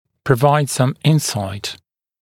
[prə’vaɪd sʌm ‘ɪnˌsaɪt][прэ’вайд сам ‘инˌсайт]дать некоторое понимание о сути ч.-л.